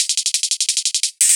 Index of /musicradar/ultimate-hihat-samples/175bpm
UHH_ElectroHatB_175-05.wav